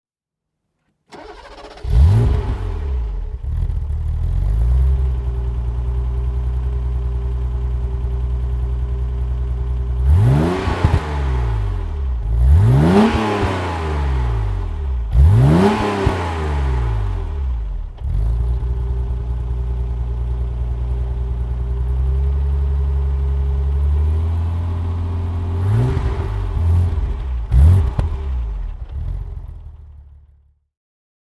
Motorsounds und Tonaufnahmen zu Alpina B10 3.5 Fahrzeugen (zufällige Auswahl)